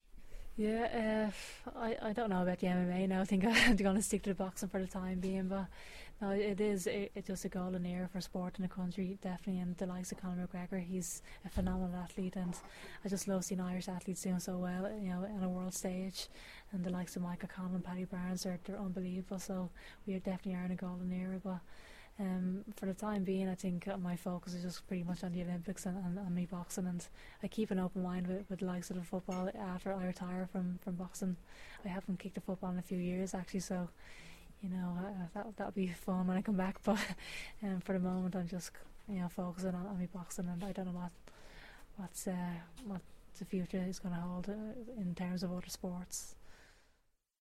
=== She was speaking at a Sky Sports Living for Sport event to announce 12 new athlete mentors.